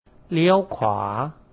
리여우 콰 - 오른쪽으로 도세요 เลี้ยวขวา